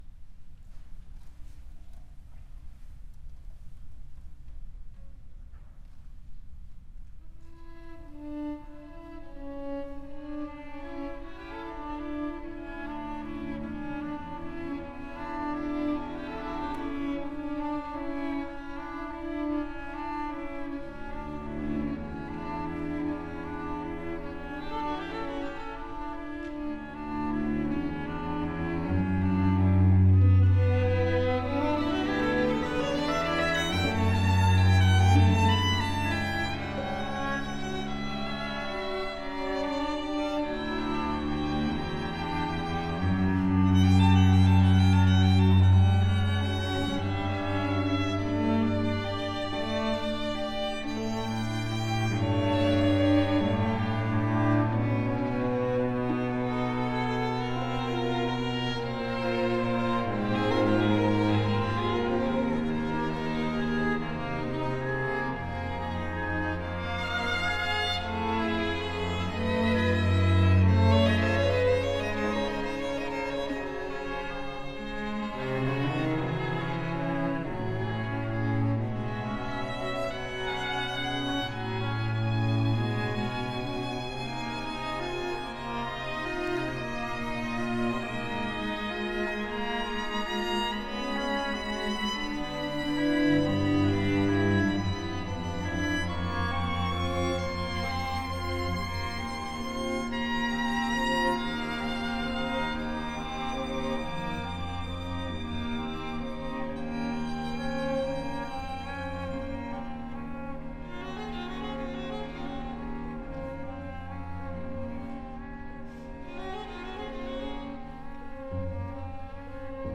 Song cycle for bariton and string quartet (version A)